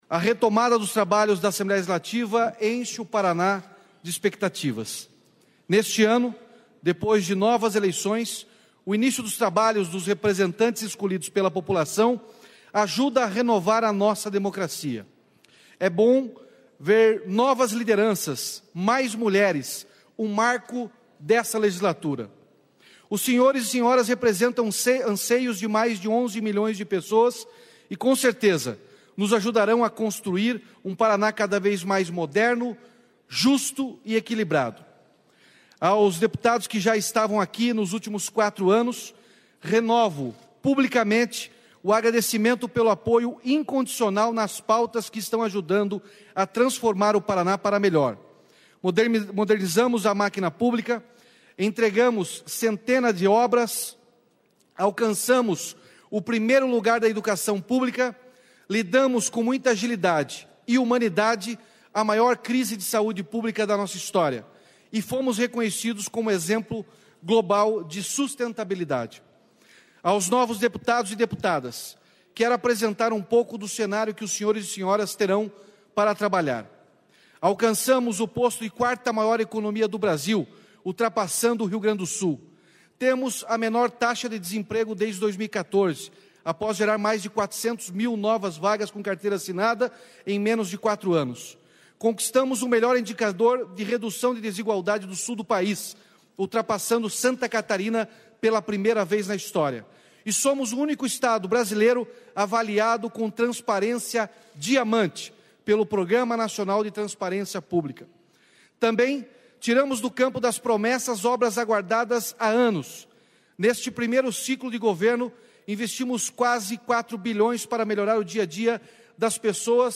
Confira o discurso do governador Ratinho Junior na abertura dos trabalhos da Assembleia
DISCURSO - RATINHO JR.mp3